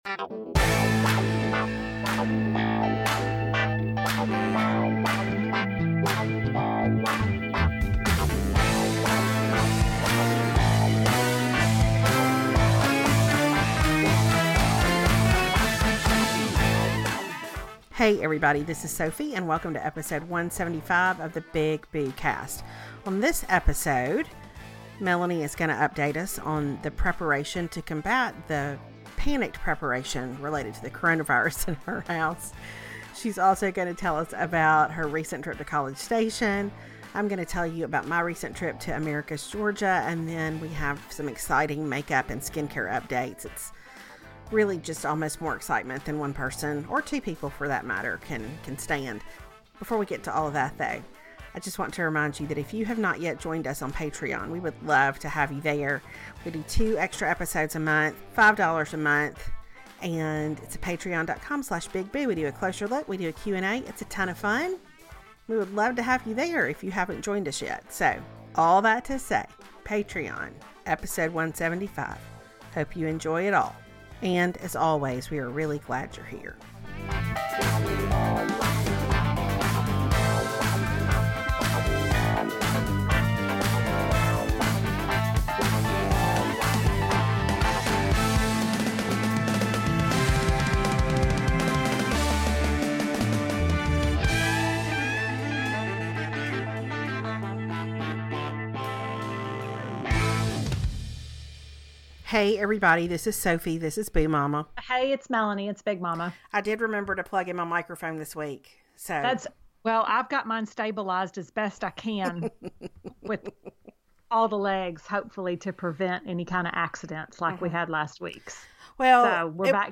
I am delighted to tell you that I remembered to plug in my microphone for this episode, so that's a win right out of the gate, isn't it?